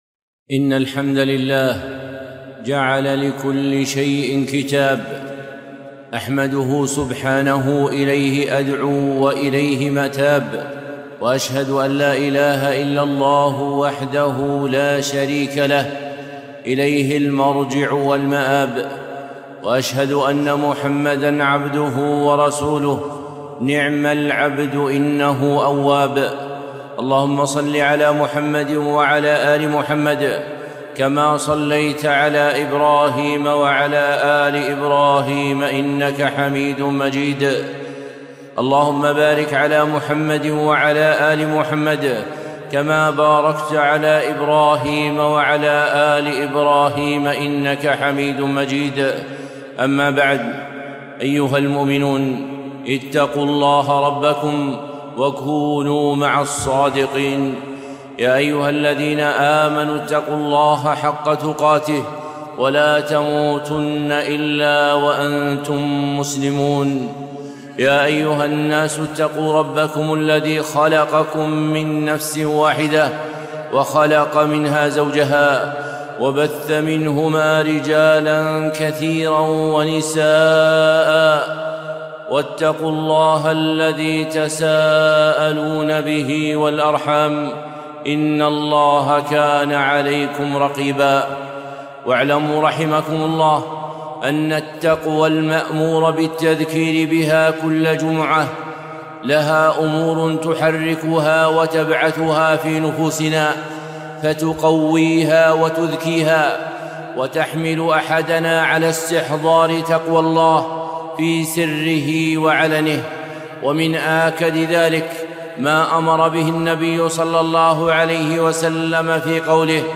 خطبة - اذكروا هاذم لذاتكم